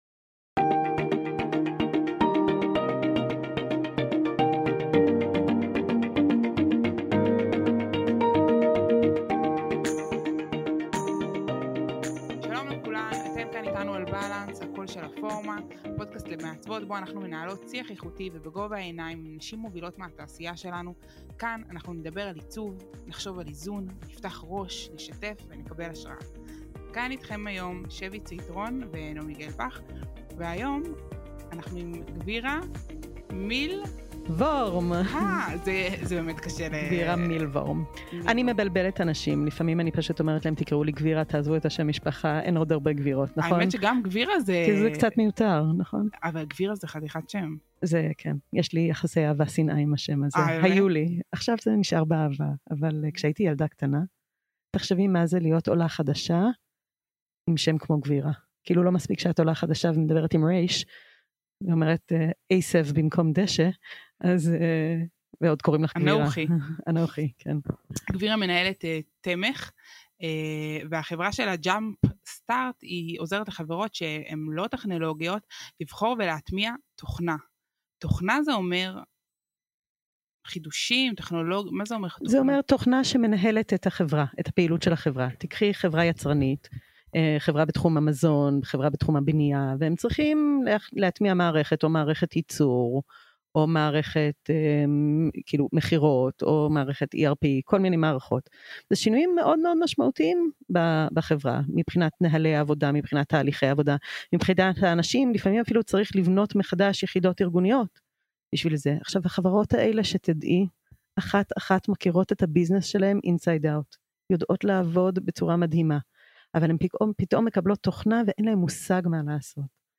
בשיחה איכותית ורצופת תובנות אמת, פתחנו נושאים ונגענו גם בנקודות רגישות לפחות אצלנו. על חדשנות וחידושים, על איזון בית עבודה ועל ביטוי אישי מקצועי, למה בשביל כל המשפחה אנחנו חייבות למלא קודם כל את עצמנו.